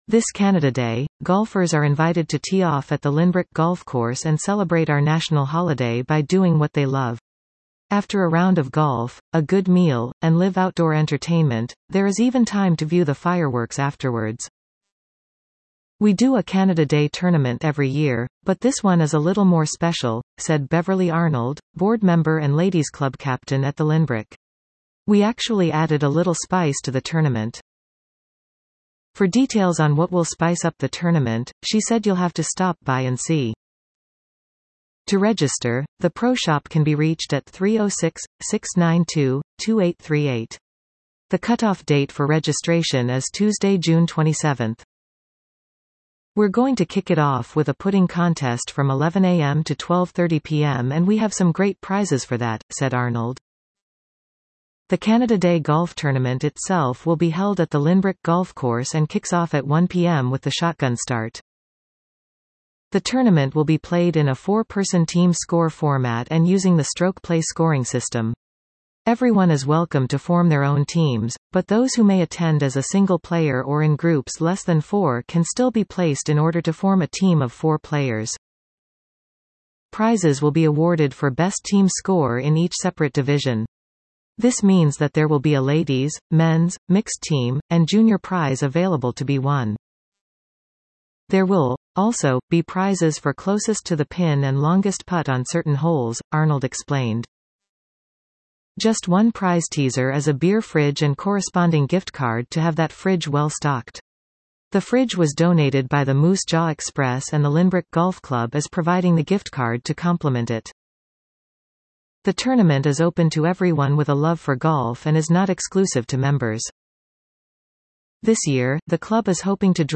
Facebook Advertisement Expand Listen to this article 00:04:09 This Canada Day, golfers are invited to tee off at the Lynbrook Golf Course and celebrate our national holiday by doing what they love.